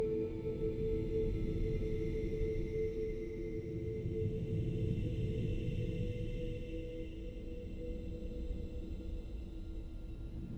I am more concerned with impercetible sounds in my recordings and this was my focus during the workshop, although I did record some sounds in the stairwell and then some smaller sounds.
ste-033-paulstretch-2.wav